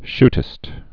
(shtĭst)